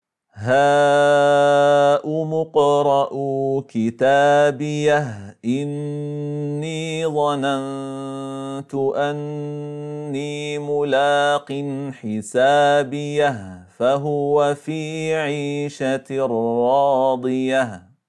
Enligt Ĥafş från ‘Aşim (في روايةِ حفصٍ عن عاصمٍ) läses det med en sukun vid både fortsättnig och stopp, som i: